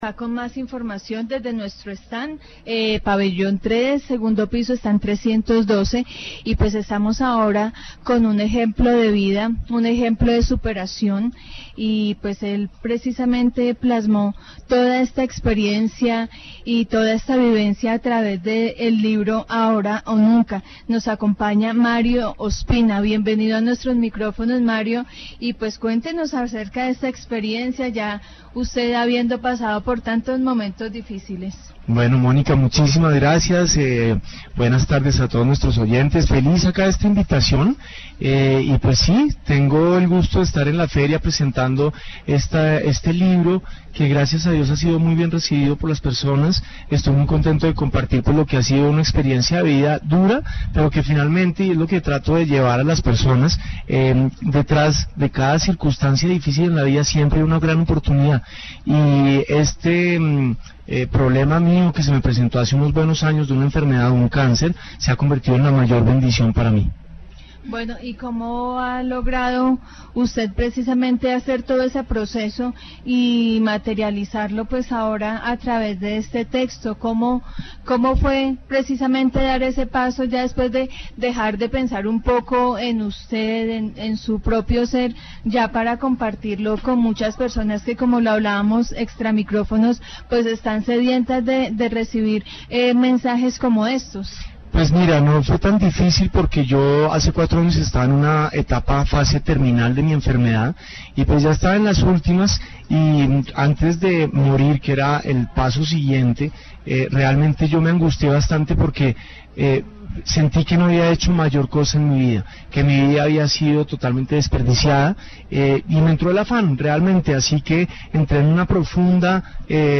Programas de radio